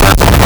Player_Glitch [14].wav